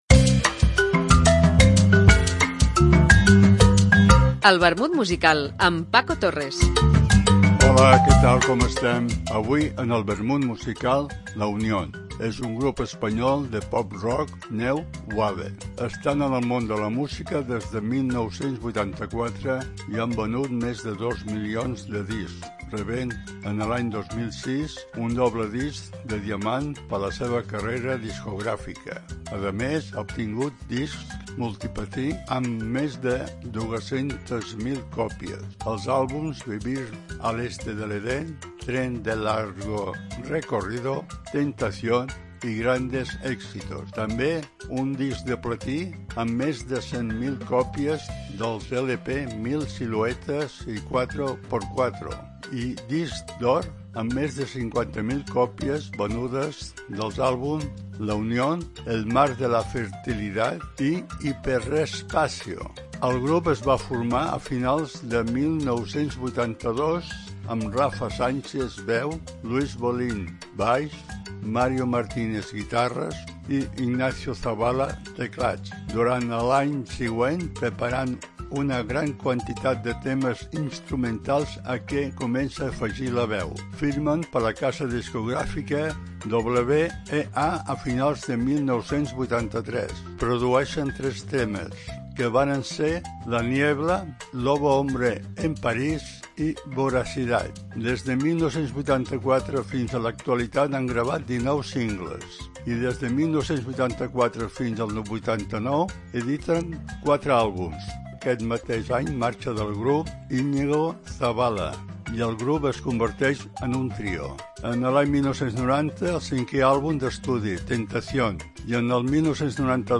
trios...etc. Una apunts biogràfics acompanyats per una cançó.